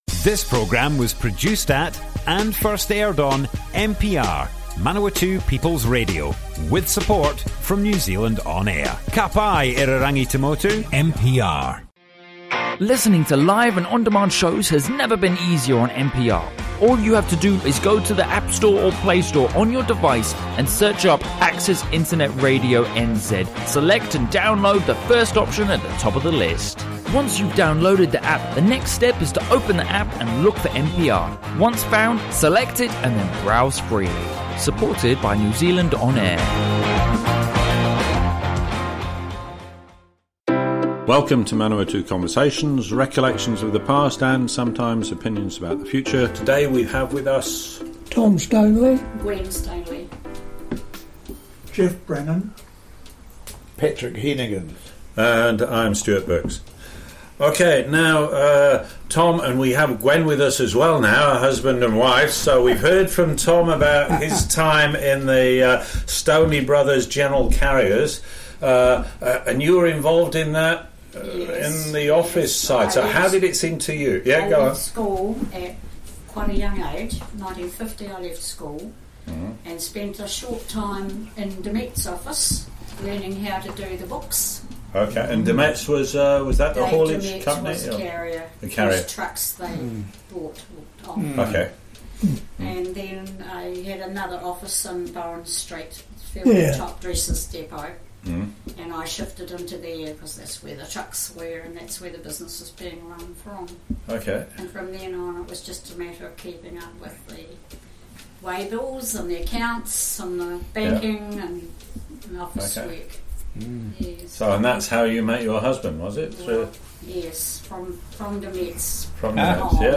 Manawatū Conversations More Info → Description Broadcast on Manawatū People's Radio 30 April, 2019.
oral history